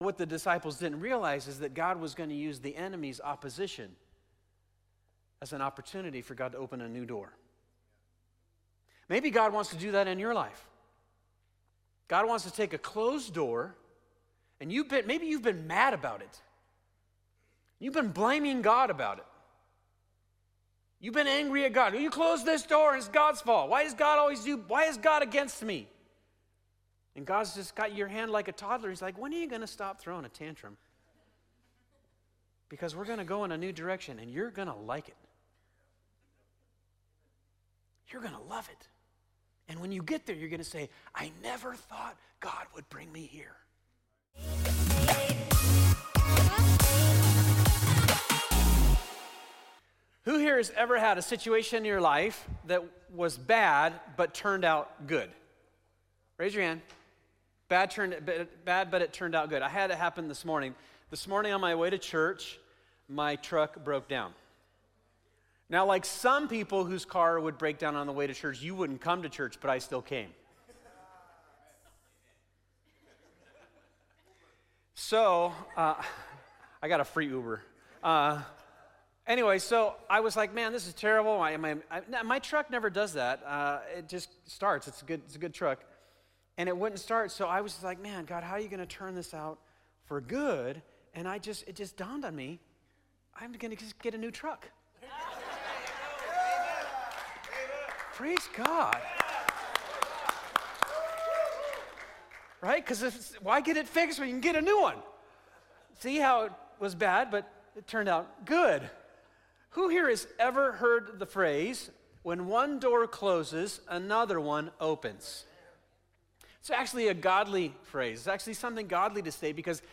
2025 The After Party Church Grief Joy Sunday Morning This is part 12 of "The After Party